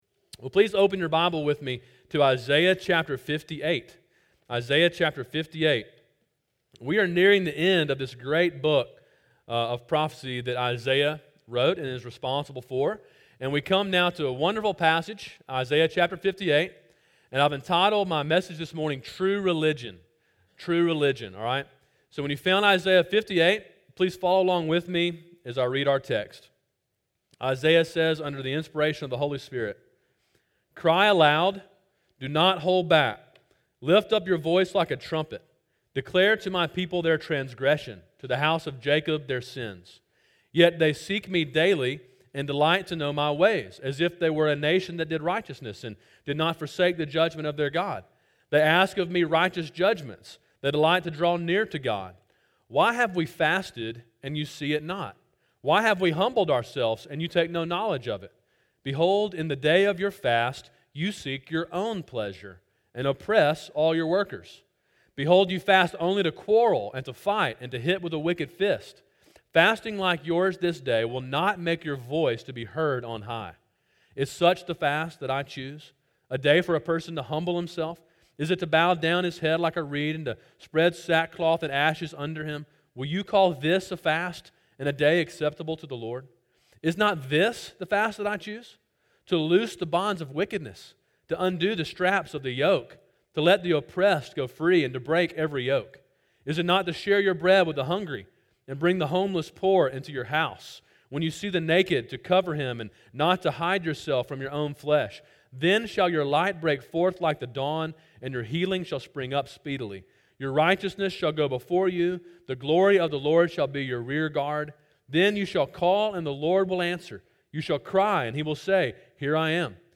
Sermon: “True Religion” (Isaiah 58) – Calvary Baptist Church